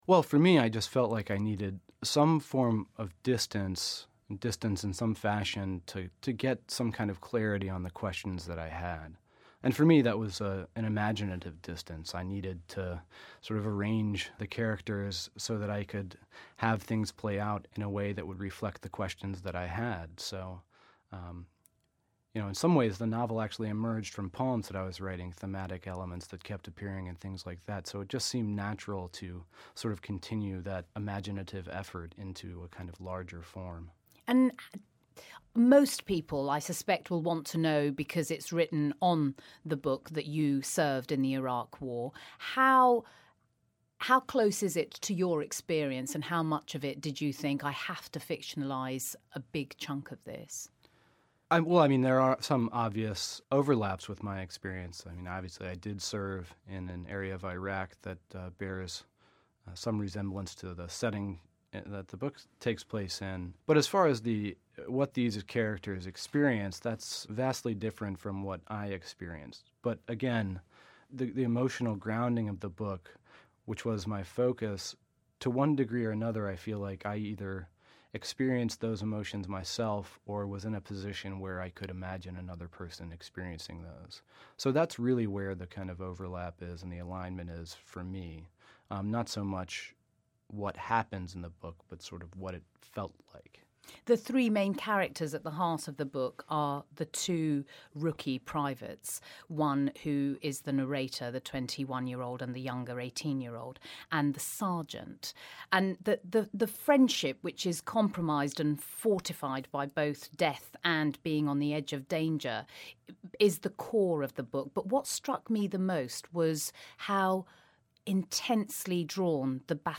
Kevin Powers interview
Interview with Yellow Birds author Kevin Powers